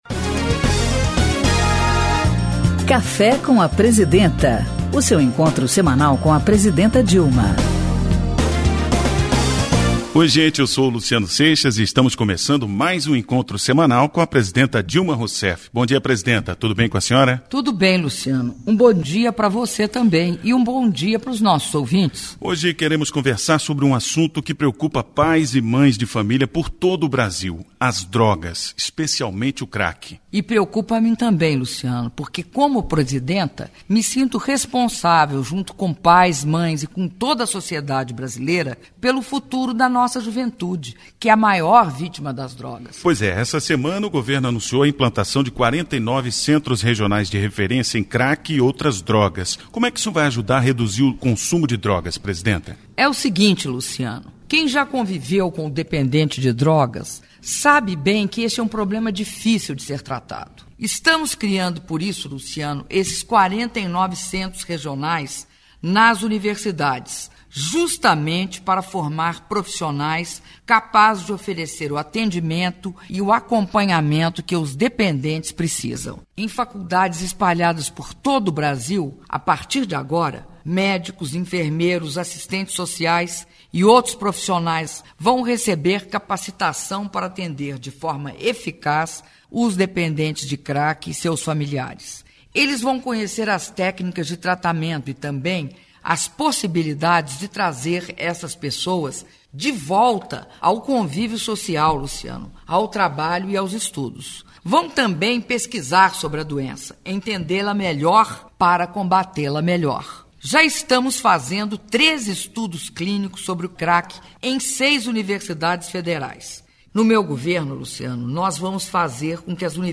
Programa de rádio “Café com a Presidenta”, com a Presidenta da República, Dilma Rousseff